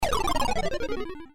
gameover.mp3